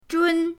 zhun1.mp3